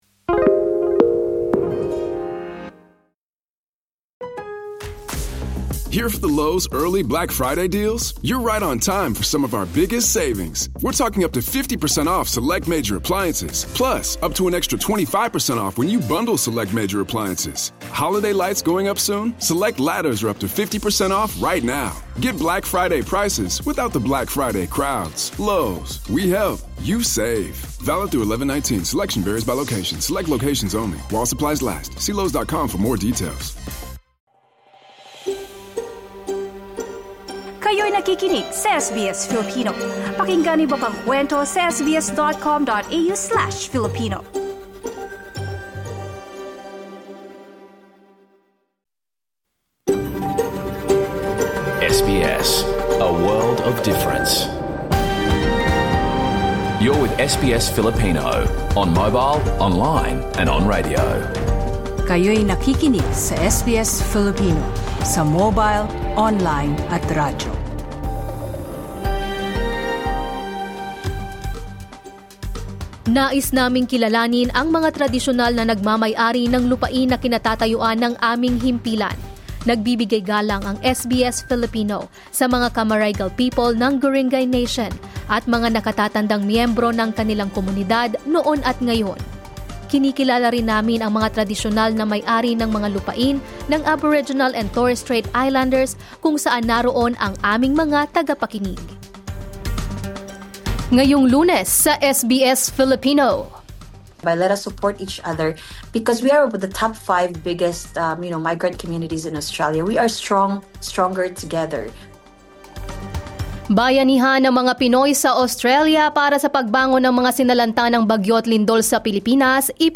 SBS Filipino Radio Program, Monday 17 November 2025